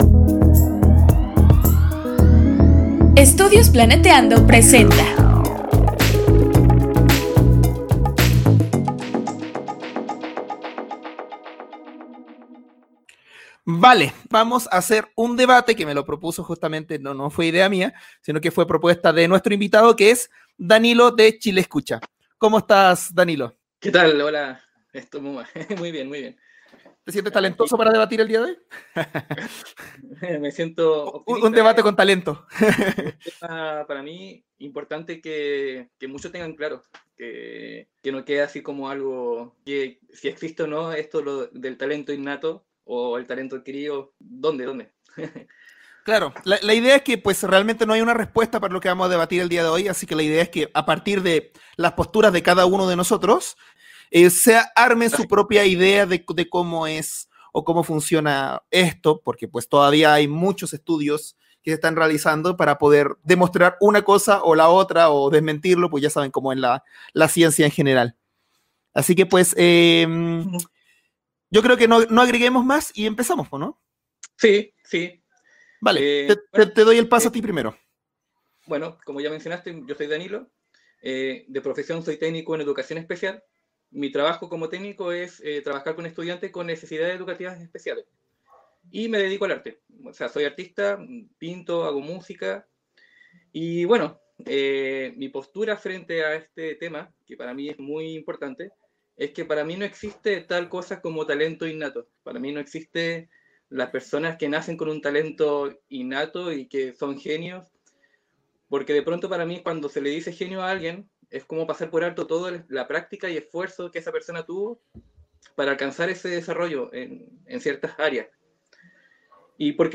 Talento ¿Natural o Adquirido? Debate
Talento-Natural-o-Adquirido-Debate.mp3